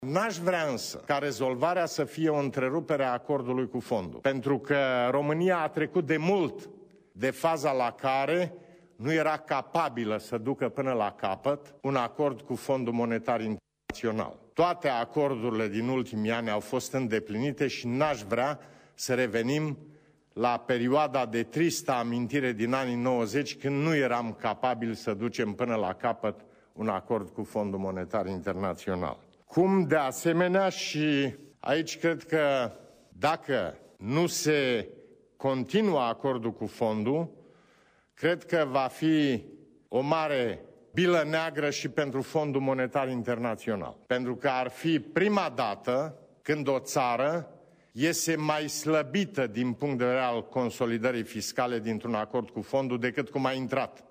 Preşedintele Traian Băsescu a susţinut, în această seară, o declaraţie la Palatul Cotroceni.
4-dec-rdj-20-Traian-Basescu-FMI.mp3